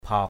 bhaok.mp3